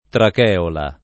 DOP: Dizionario di Ortografia e Pronunzia della lingua italiana
tracheola [ trak $ ola ]